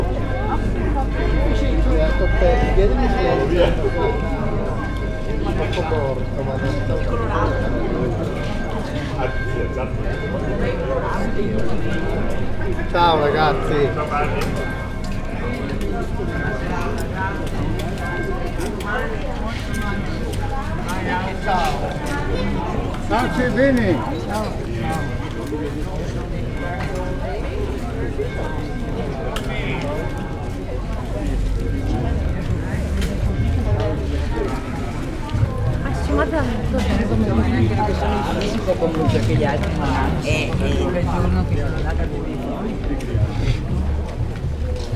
elhalkuloharangozas_beszedesjarokelok_velence_omni_sds00.44.WAV